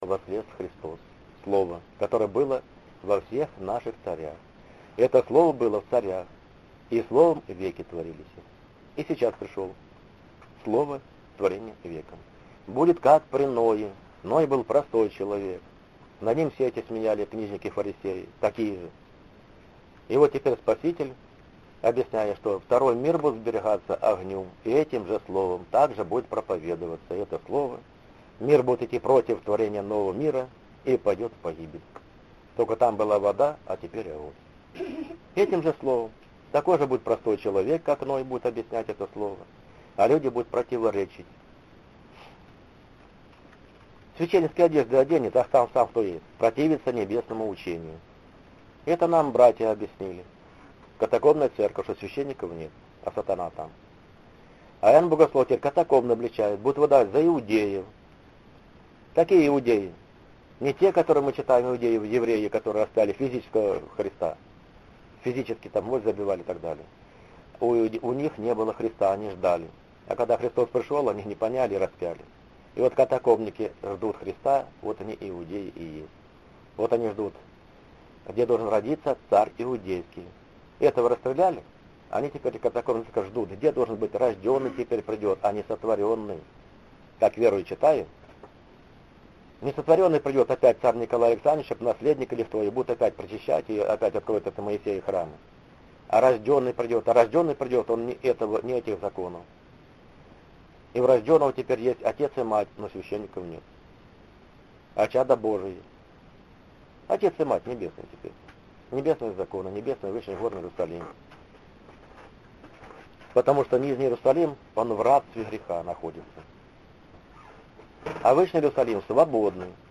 Звуковая проповедь